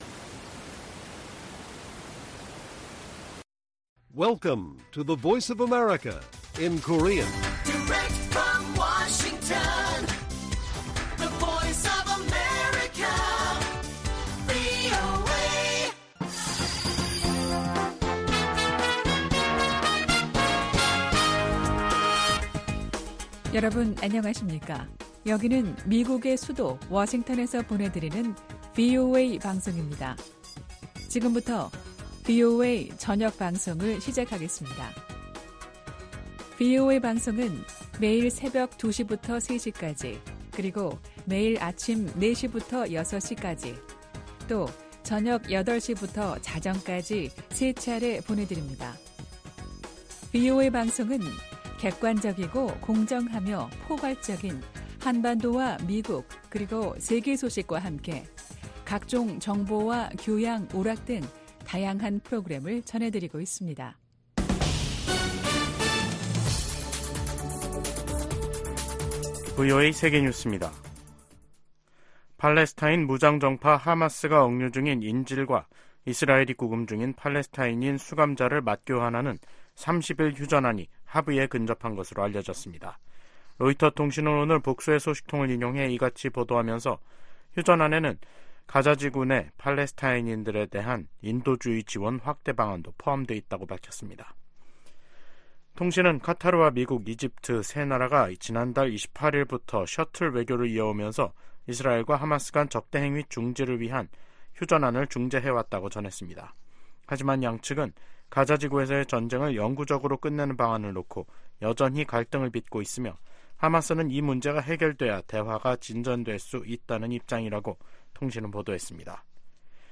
VOA 한국어 간판 뉴스 프로그램 '뉴스 투데이', 2024년 1월 24일 1부 방송입니다. 북한이 서해상으로 순항미사일 여러 발을 발사했습니다.